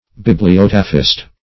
Search Result for " bibliotaphist" : The Collaborative International Dictionary of English v.0.48: Bibliotaph \Bib"li*o*taph\, Bibliotaphist \Bib`li*ot"a*phist\, n. [Gr.